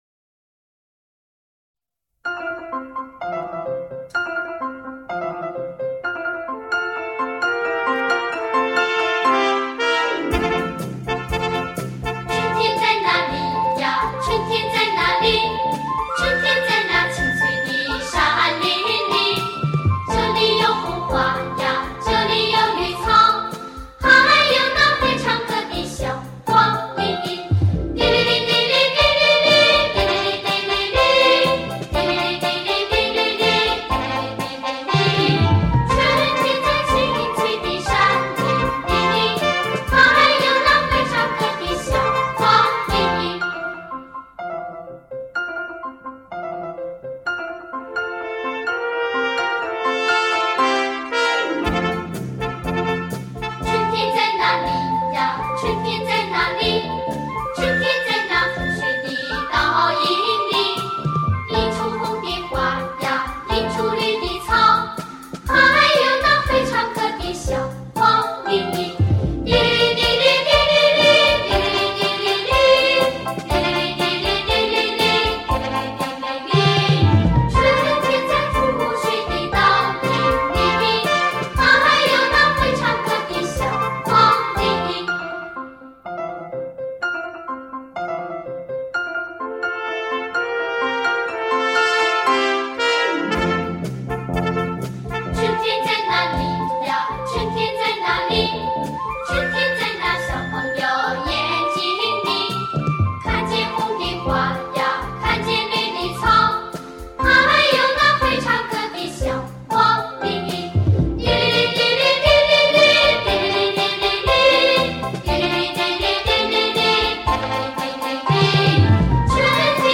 经典童声
天籁童声，重温孩提时代的美好时光，唤起心底最无私的童真记忆，叫醒那颗沉睡已久麻木的心，抚平成长的忧伤与烦恼。